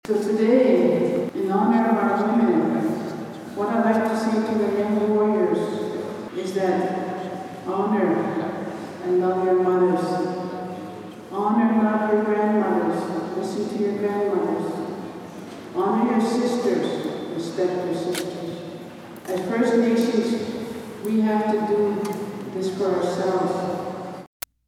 Councilor Crystal shared a message to all the young warriors in attendance.